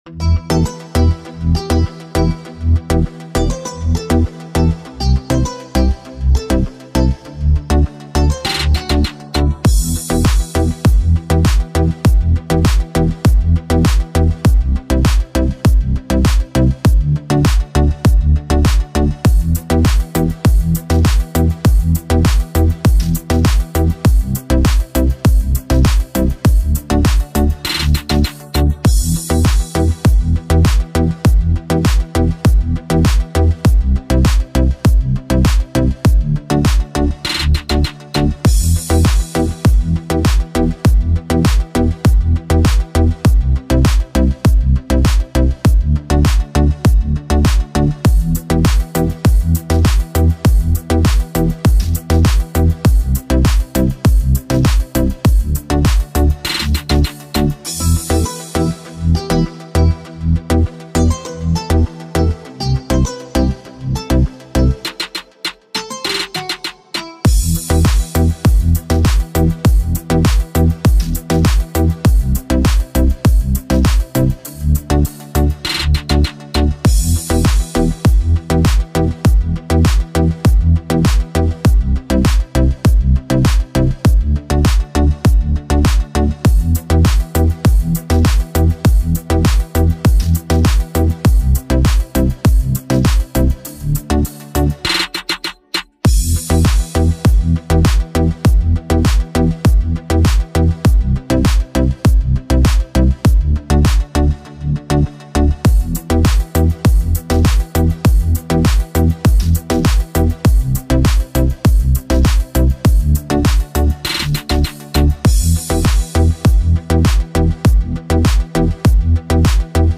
Only Beat) (Instrumental